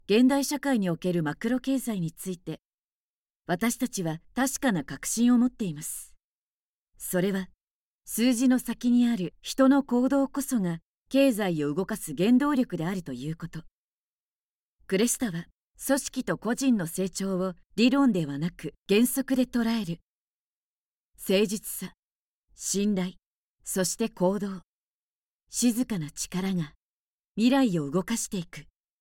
中低音～低音の響く声が特徴です。透明感のあるナレーションや、倍音を活かした語りを得意としています。
シリアス、迫真に迫った